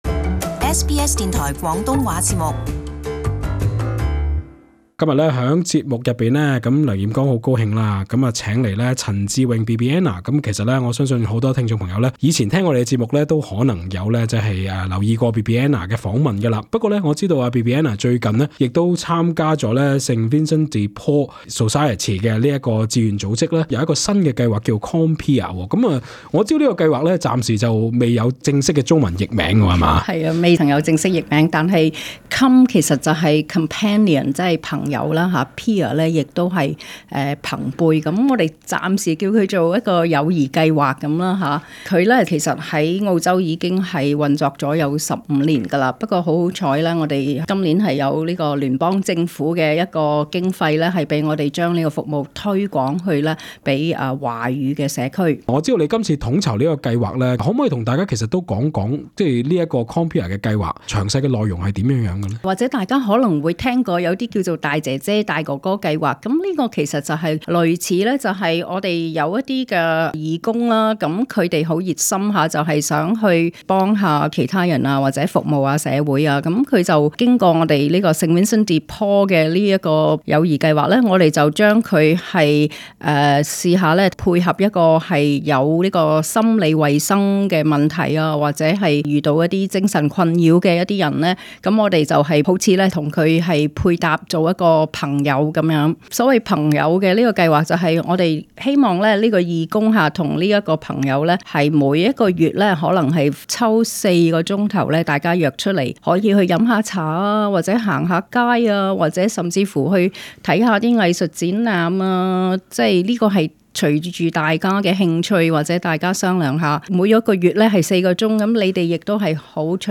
【社區專訪】Compeer計劃如何幫助華人社區的孤獨者？